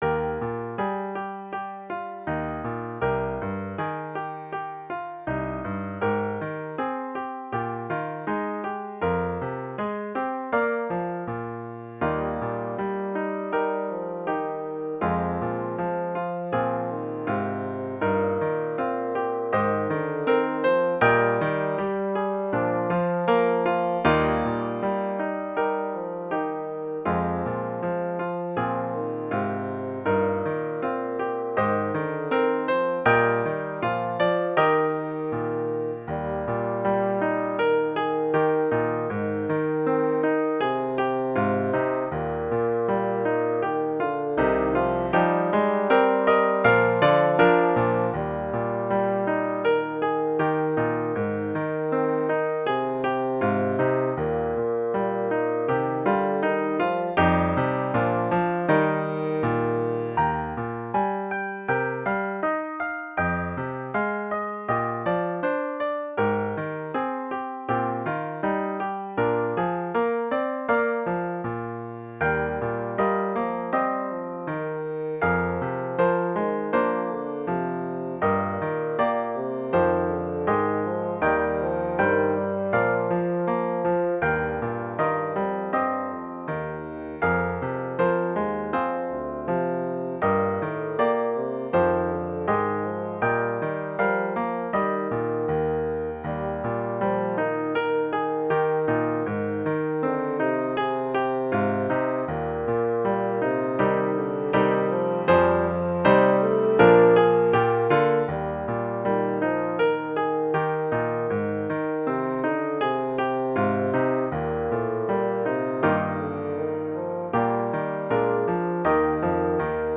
Intermediate Instrumental Solo with Piano Accompaniment.
Christian, Gospel, Sacred.
puts the sacred theme to a gentle, meditative mood.